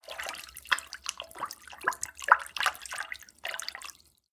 water09
bath bubble burp click drain dribble drip dripping sound effect free sound royalty free Nature